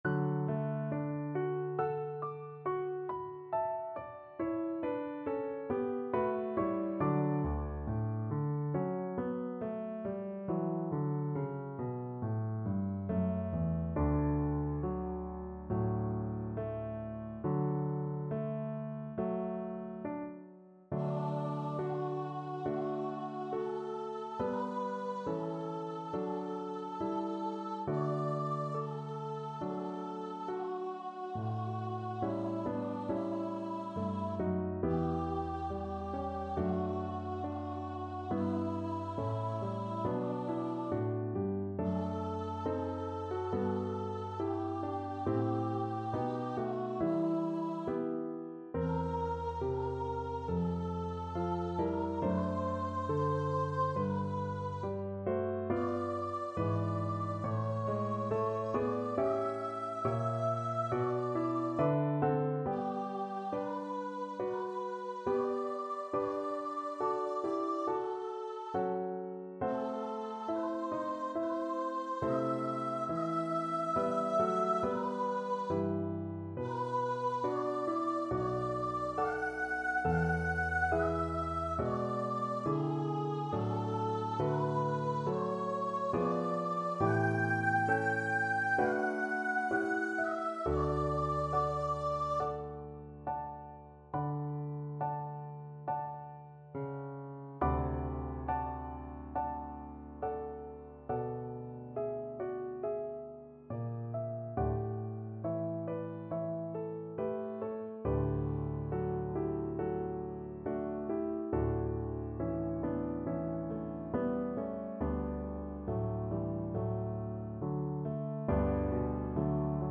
D major (Sounding Pitch) (View more D major Music for Vocal Duet )
Slow =c.69
2/2 (View more 2/2 Music)
Classical (View more Classical Vocal Duet Music)